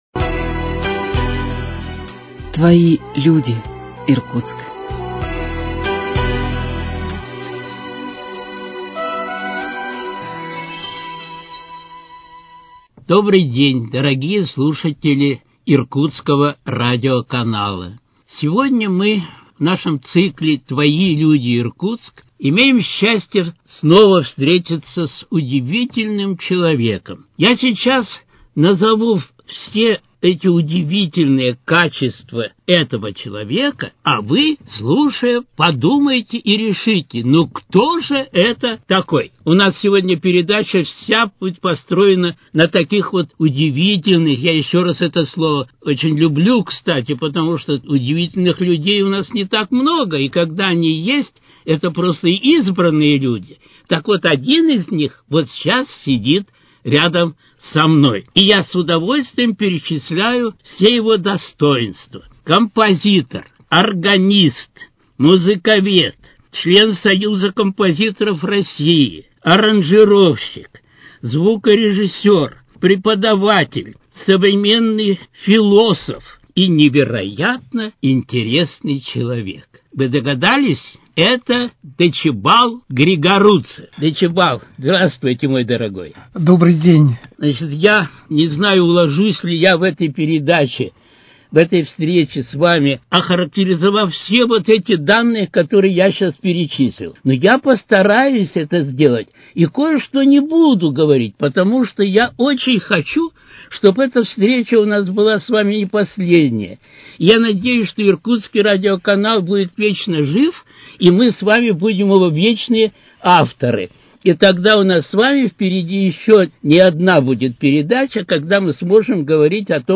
Твои люди, Иркутск: Беседа с композитором